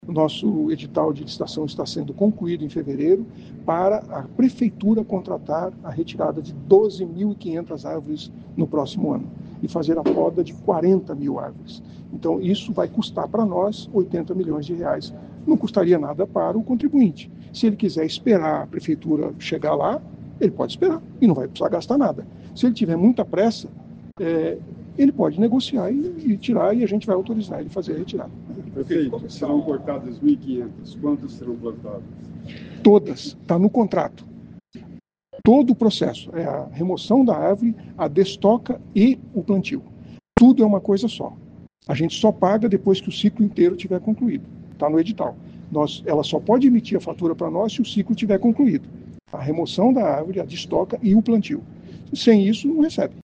O prefeito Silvio Barros disse em coletiva de imprensa que em fevereiro será concluído um edital para a contratação de uma empresa especializada em arborização. A prefeitura vai pagar pela remoção de 12.500 árvores e poda de outras 40 mil.
Ouça o que diz o prefeito: